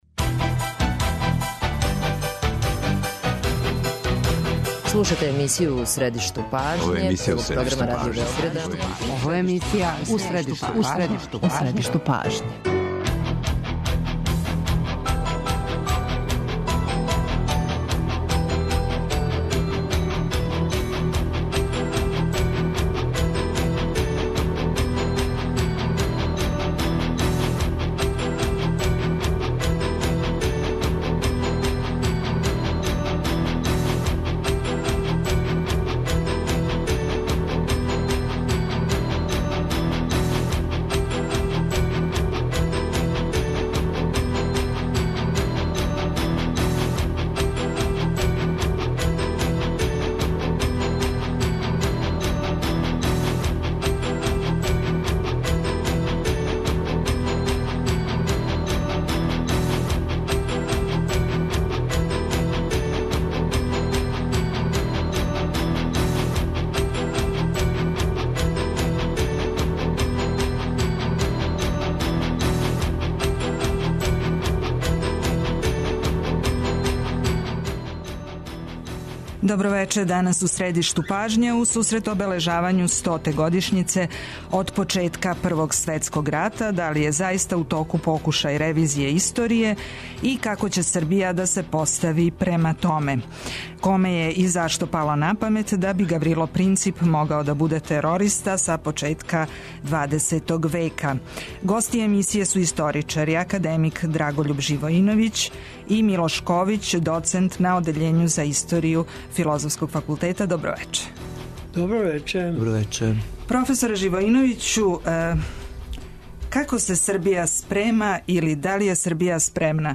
Гости емисије су историчари